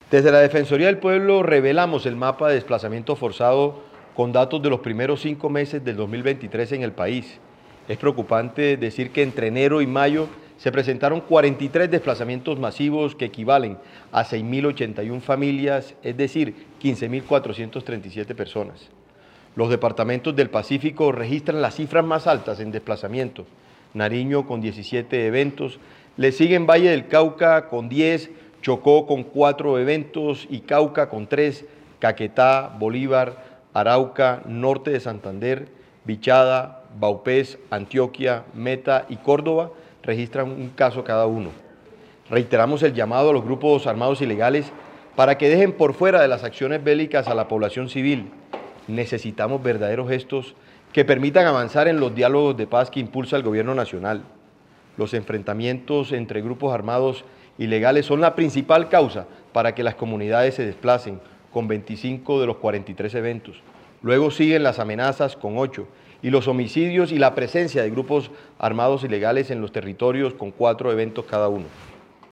Descargue y escuche las declaraciones del Defensor del Pueblo